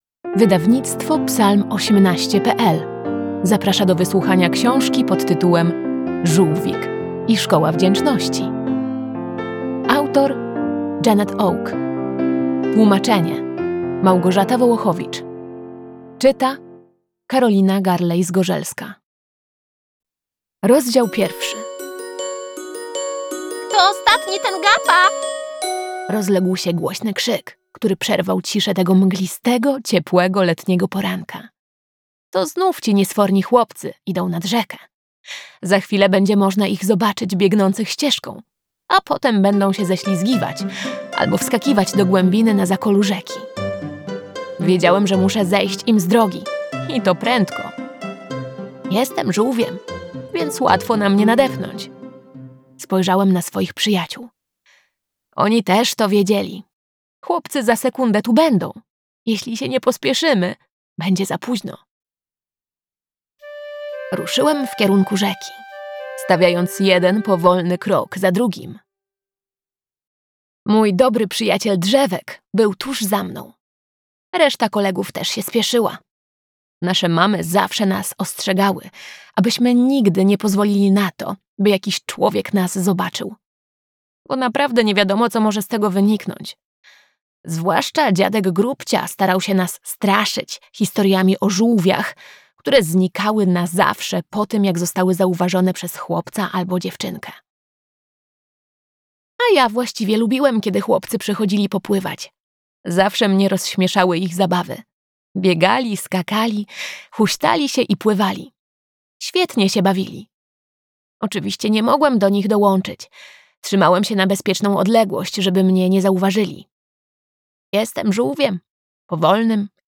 Żółwik i szkoła wdzięczności - Audiobook
01_Rozdzial_1-Zolwik_i_szkola_wdziecznosci-Janette_Oke-audiobook.mp3